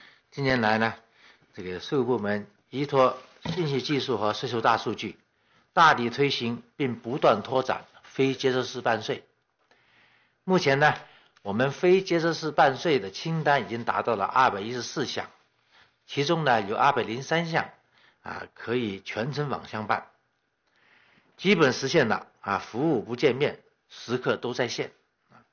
近日，国务院新闻办公室举行新闻发布会，国家税务总局相关负责人介绍《关于进一步深化税收征管改革的意见》（以下简称《意见》）有关情况。会上，国家税务总局总审计师饶立新介绍，目前203项税费业务可全程网上办。